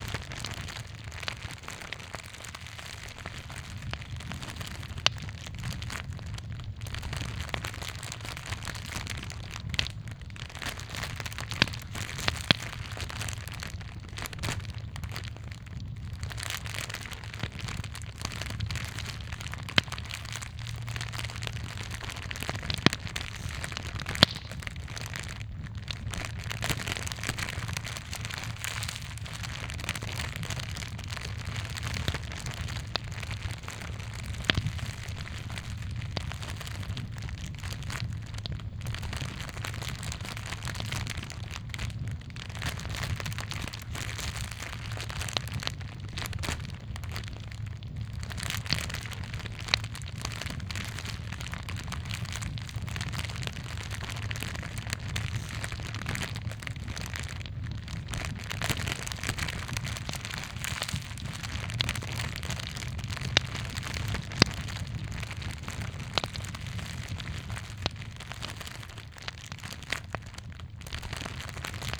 firecamp.wav